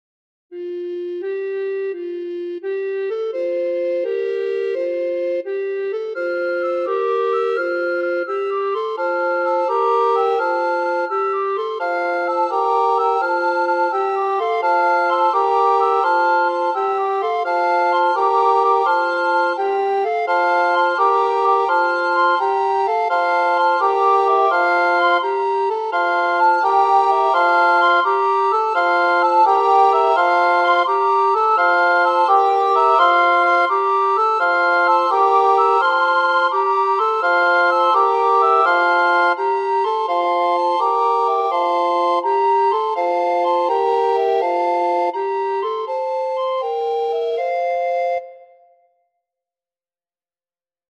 an old English Folk Song